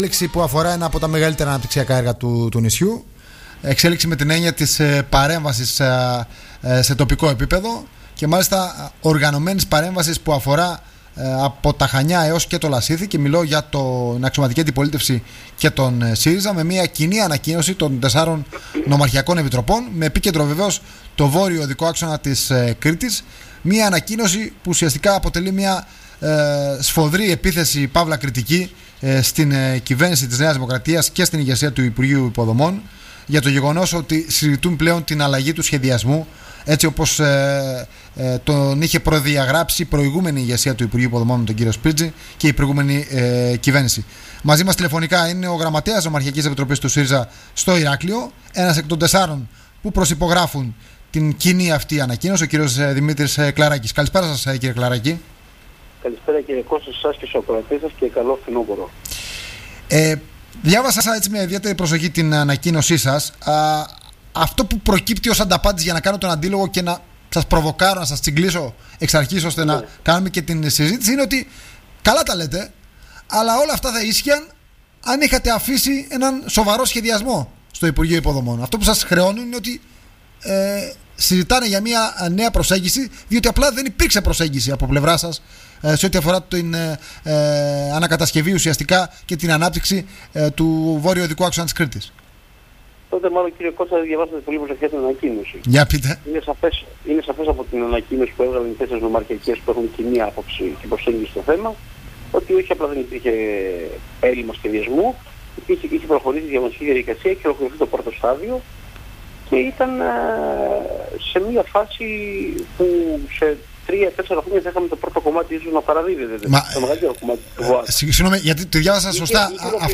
μίλησε στον Politica 89,8